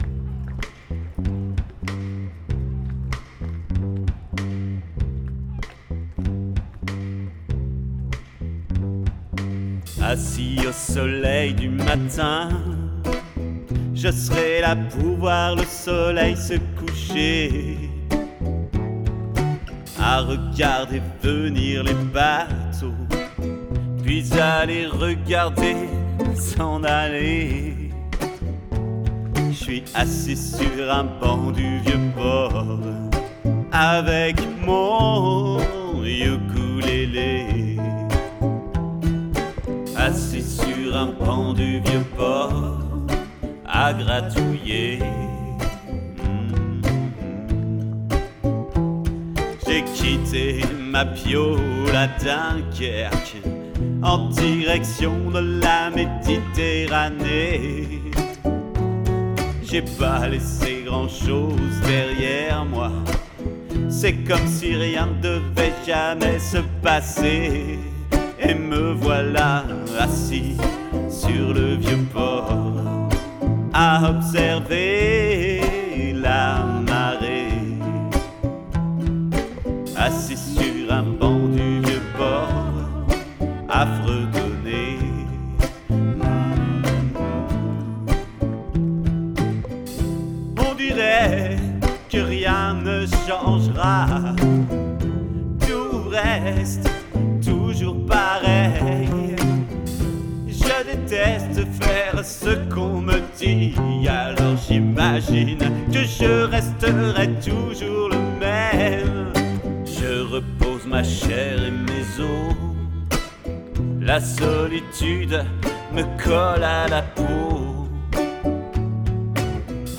# ukuleleCover